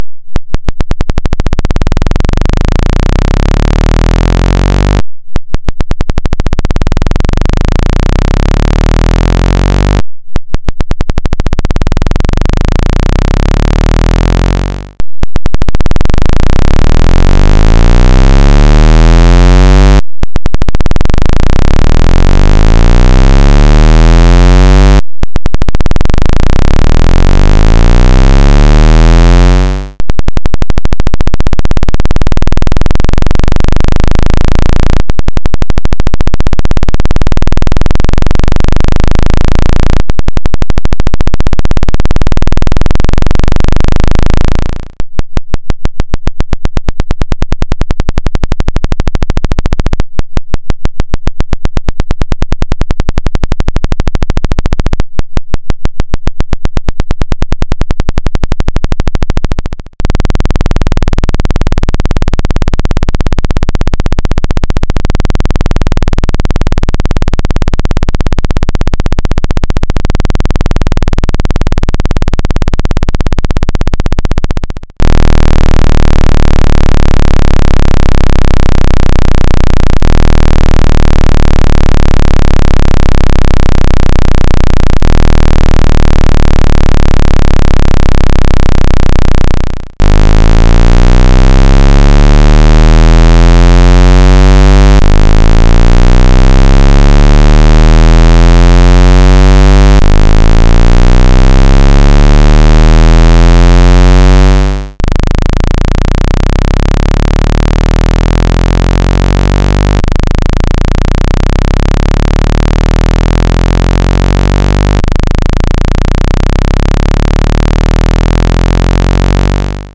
All Sounds for Speaker Cleaner. 16 in 1
Speaker Cleaner